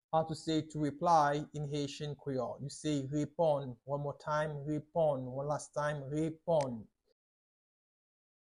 Pronunciation:
25.How-to-say-Reply-in-Haitian-Creole-–-Reponn-with-pronunciation.mp3